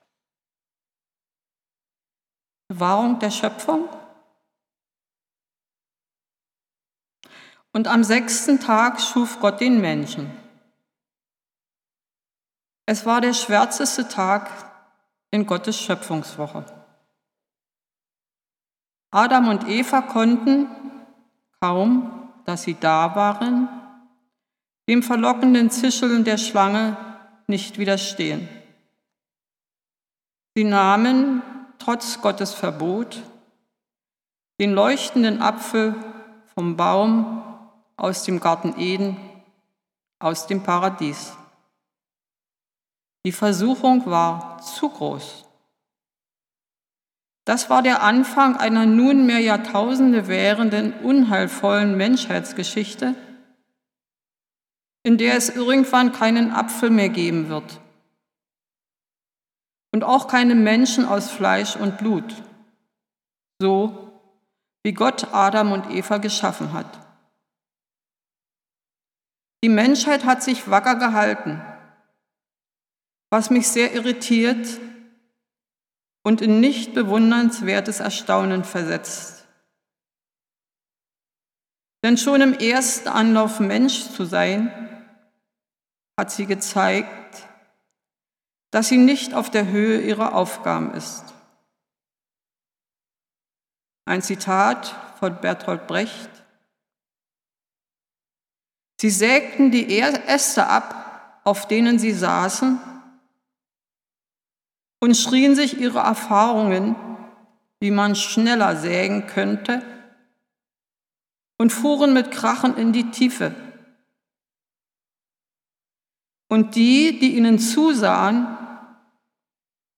Predigten | Bethel-Gemeinde Berlin Friedrichshain